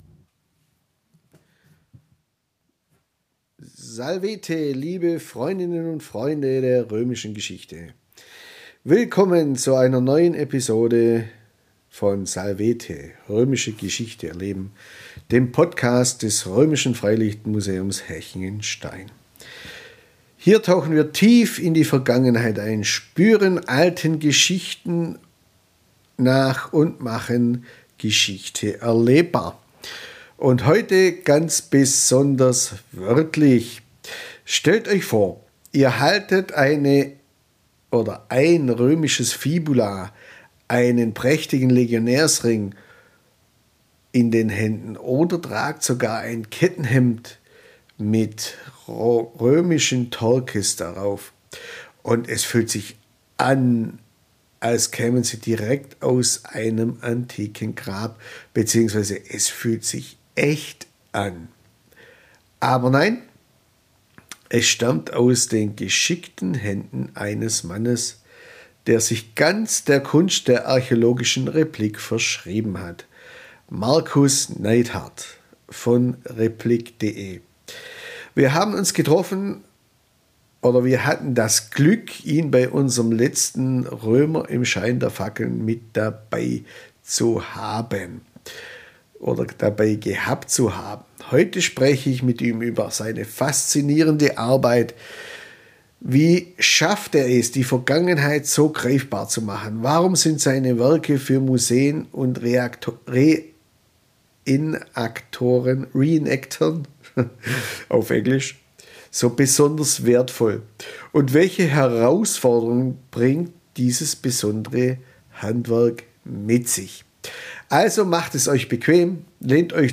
Heute spreche ich mit ihm über seine faszinierende Arbeit.